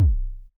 tm kick.wav